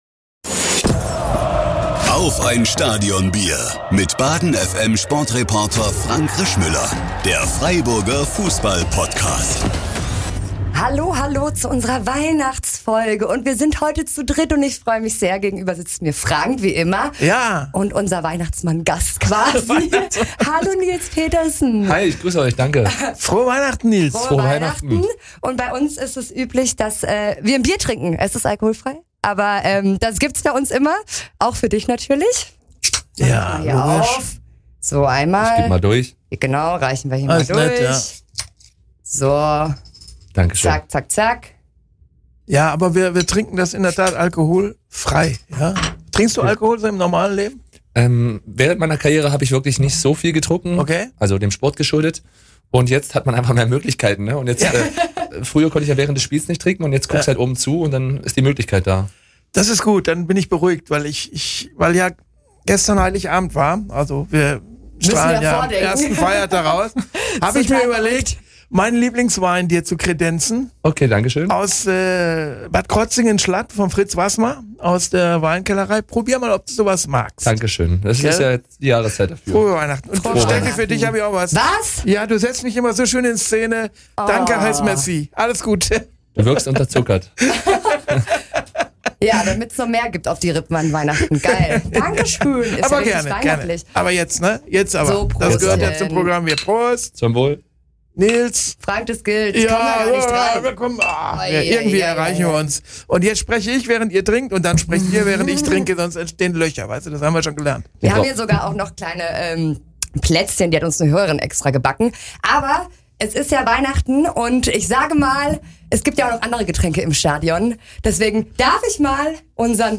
Es weihnachtet sehr und der Weihnachtsmann hat uns ein besonderes Geschenk vorbeigebracht: Nils Petersen ist zu Gast!